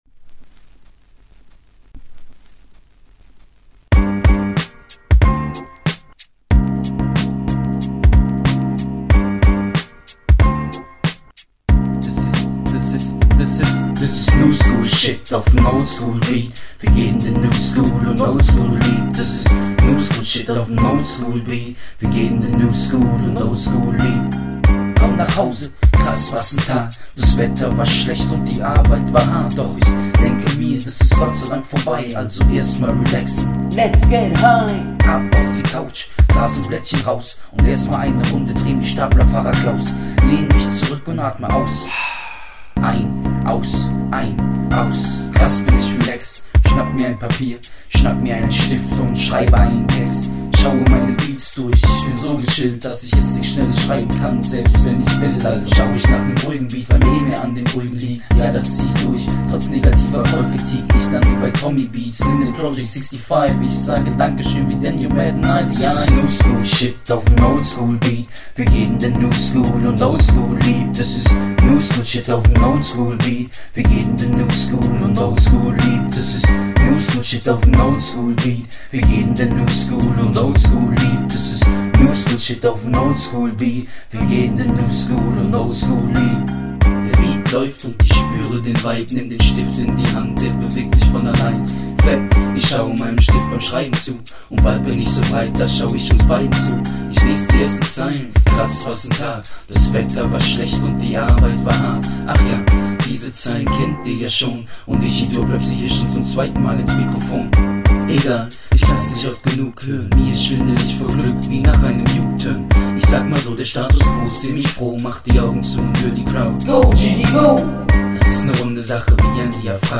Oldskoooooool... best track so far...